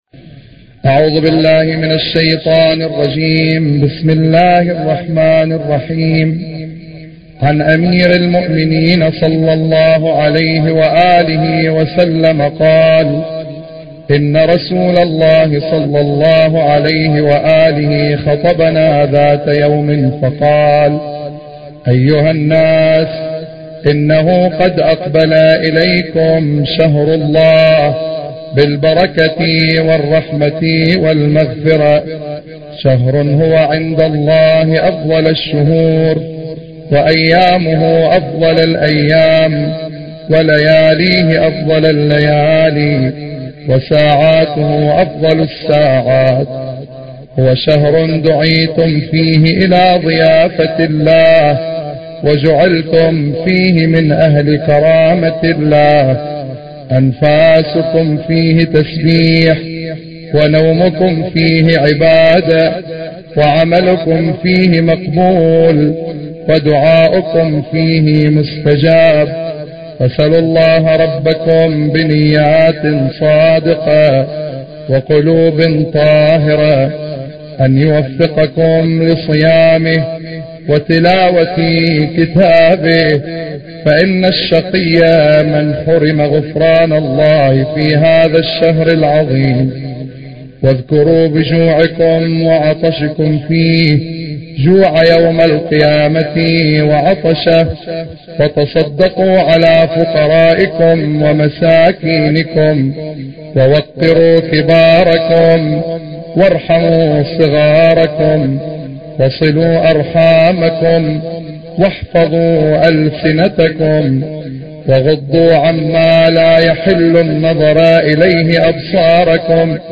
خطبة الرسول صلى الله عليه وآله في استقبال شهر رمضان